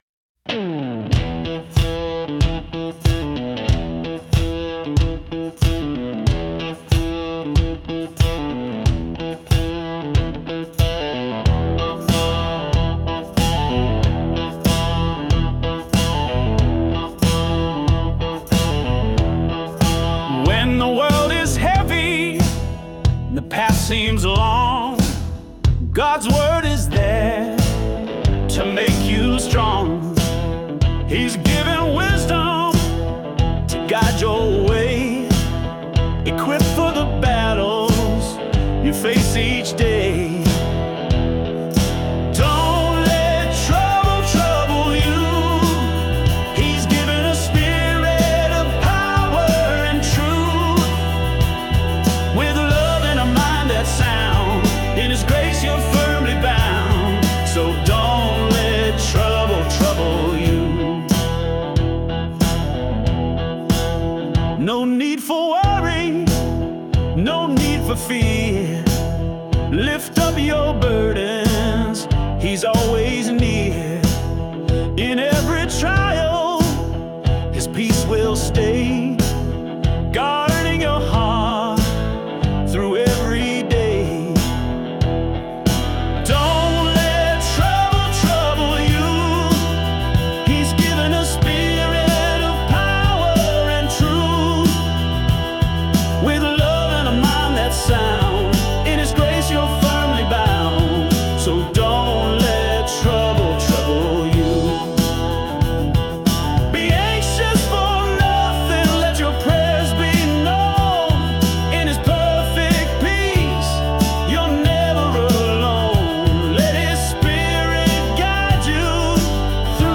Category: Christian Praise